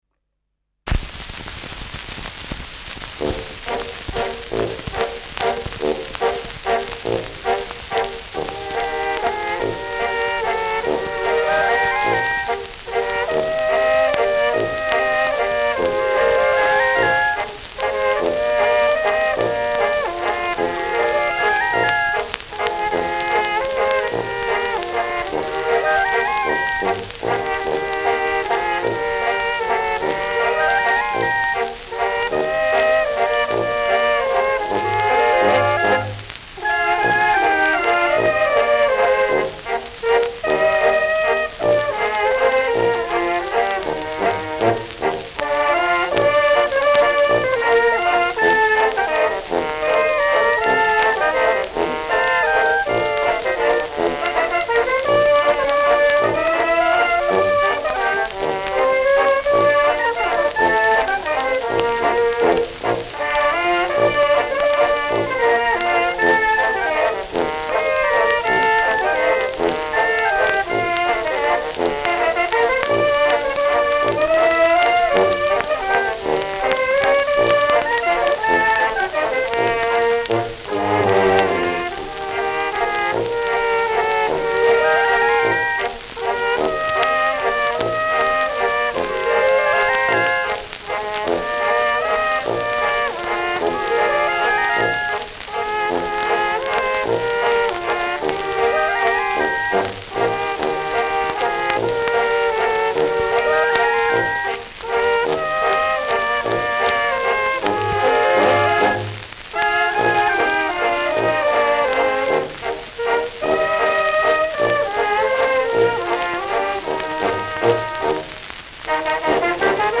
Schellackplatte
präsentes Rauschen : präsentes Knistern : leiert : abgespielt : vereinzeltes Knacken
Böhmische Bauernkapelle (Interpretation)